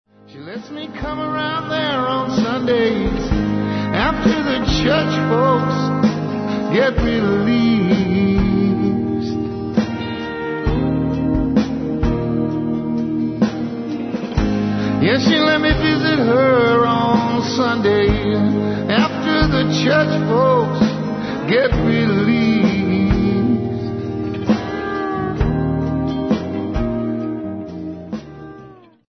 lead vocals, guitar, dulcimer
Recorded at General Store Recording